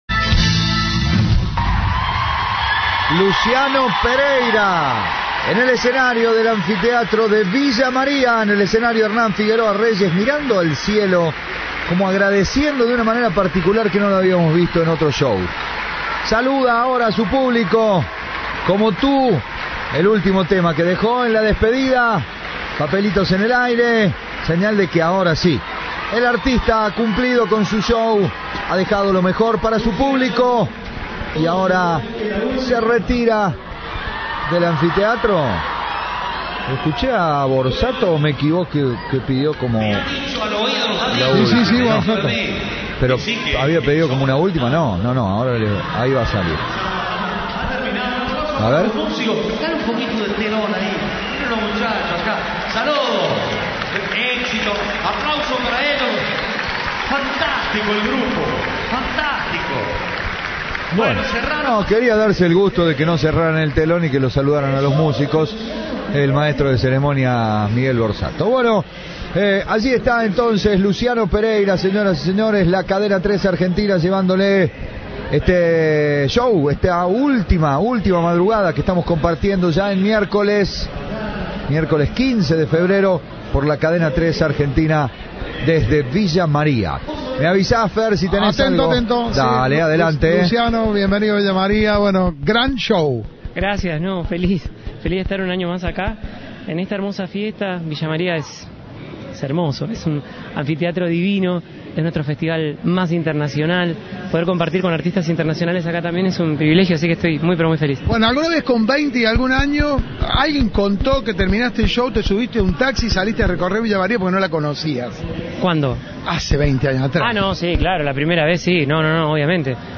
Transmisión de Cadena 3.
Informe